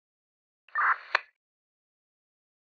walkie.mp3